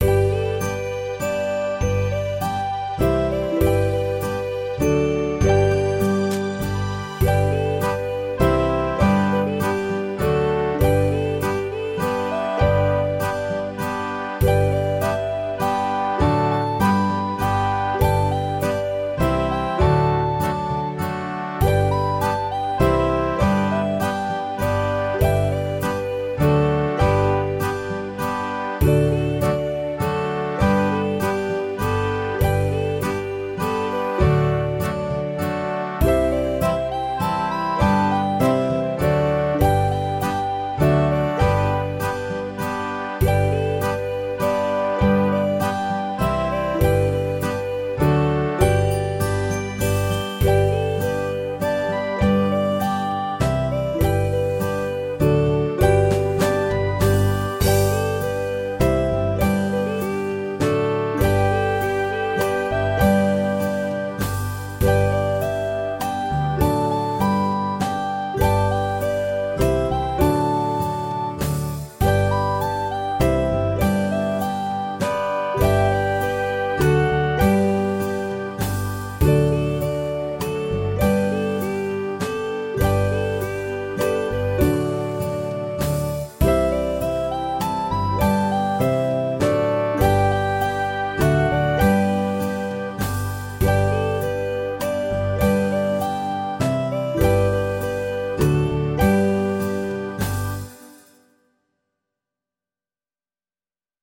童謡・唱歌のメロディ譜です。
♪演奏サンプル（自動生成）